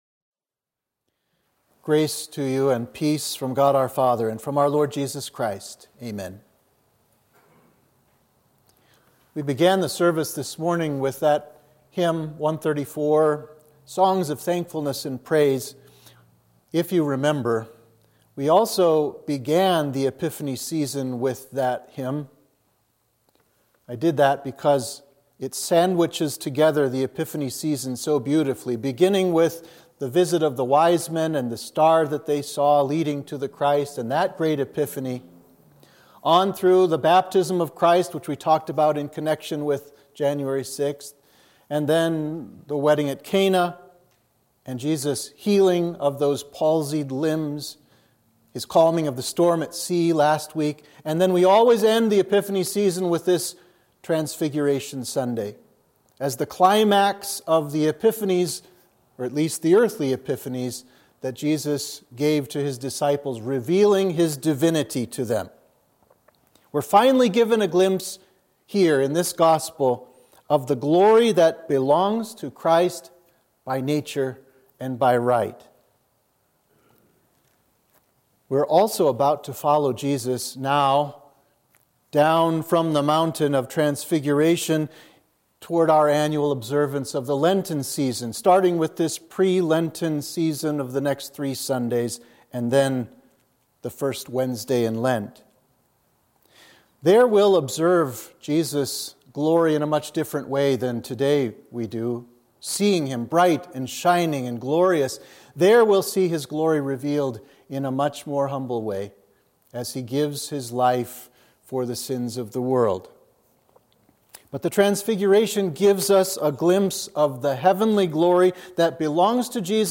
Sermon for the Transfiguration of Our Lord